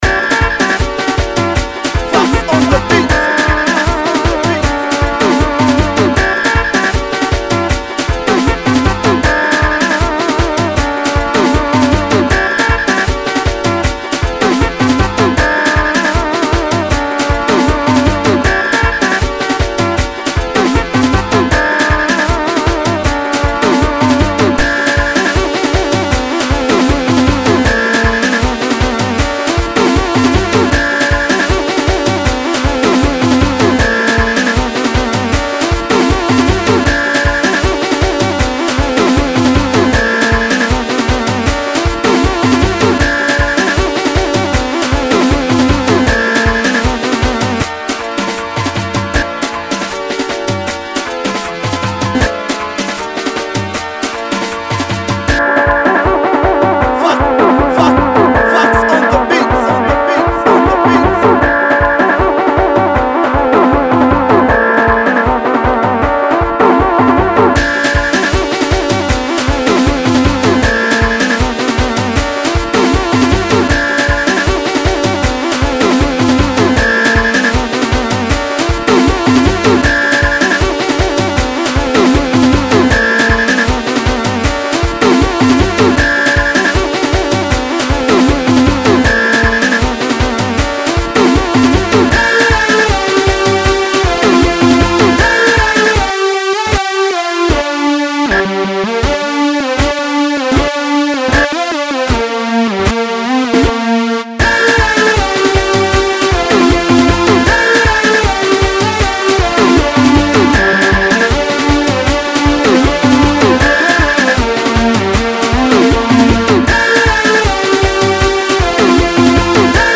Afro beat